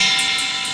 Ride 06.wav